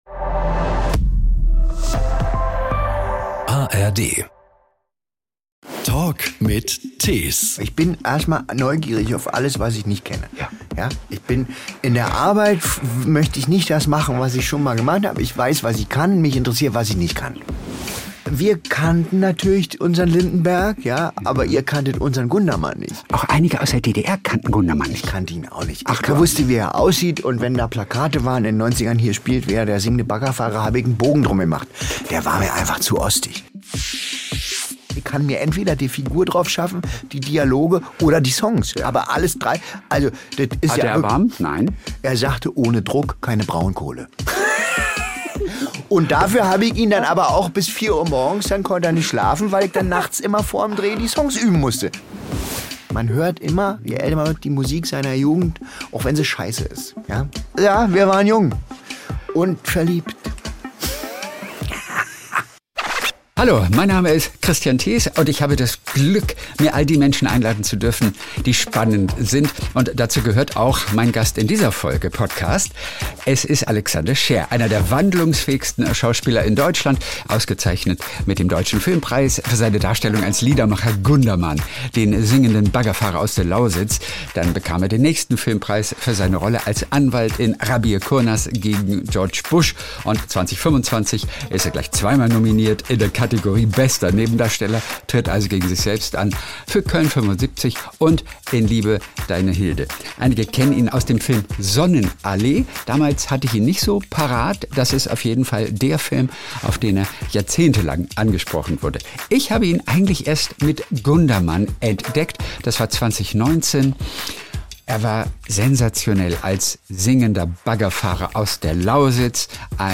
In diesem Podcast hört ihr ein fiktives Interview mit Gundermann, bei dem Alexander Scheer hörbar Spaß hatte: Er antwortet ausschließlich mit Songzeilen aus Gundermanns Liedern.